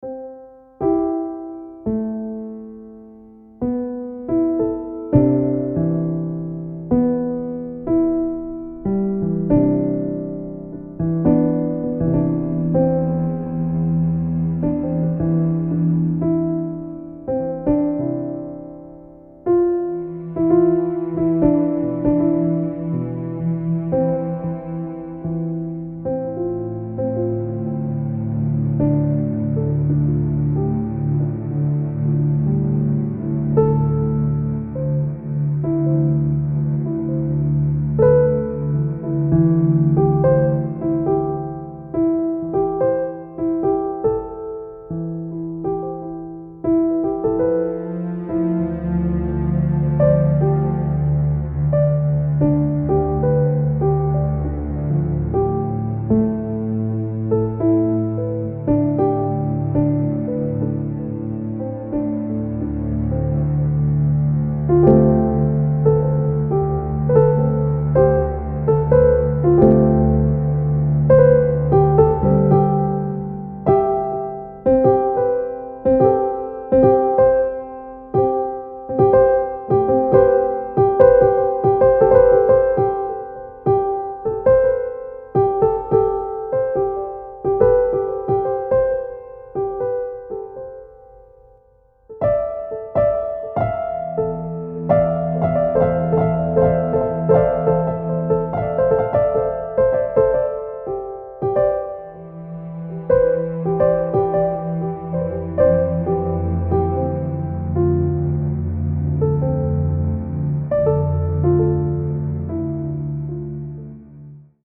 Triste Classique